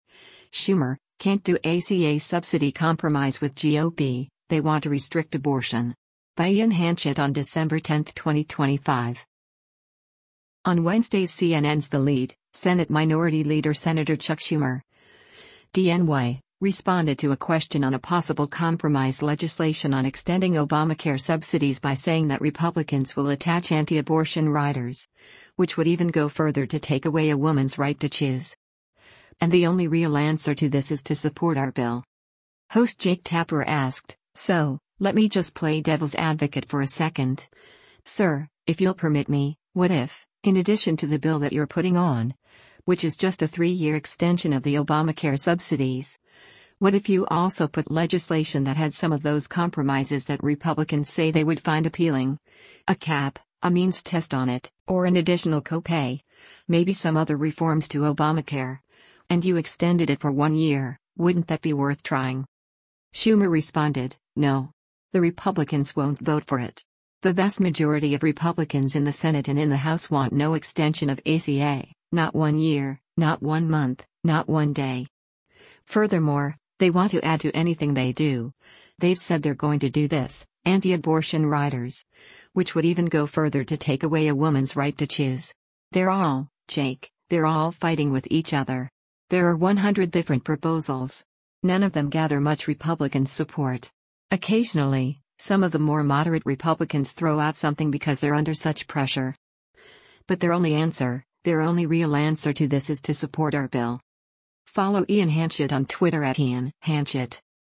On Wednesday’s CNN’s “The Lead,” Senate Minority Leader Sen. Chuck Schumer (D-NY) responded to a question on a possible compromise legislation on extending Obamacare subsidies by saying that Republicans will attach “anti-abortion riders, which would even go further to take away a woman’s right to choose.” And the “only real answer to this is to support our bill.”